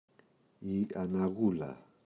αναγούλα, η [ana’γula]